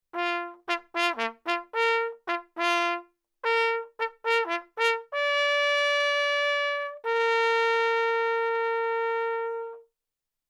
Auf unserer Website bieten wir auch unter anderem traditionelle Jagdsignale zum Anhören an.
Jagdhorn
Jagdleitersignale: